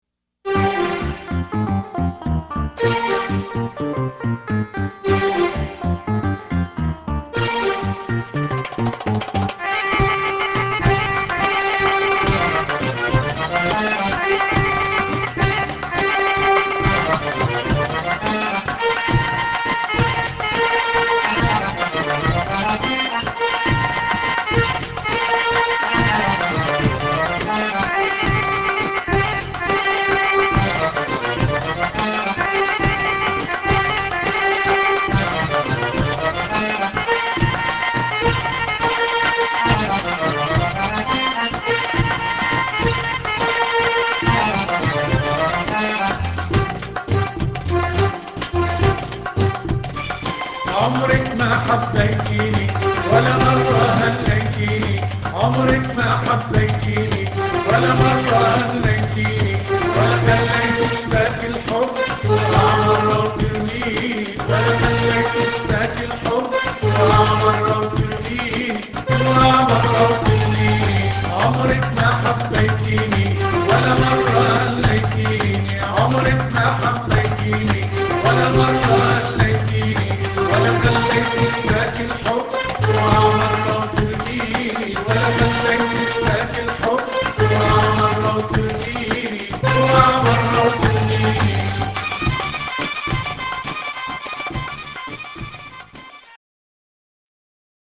Vocals, Oud, Accordion & Keyboard
Percussion
Tabla
Nay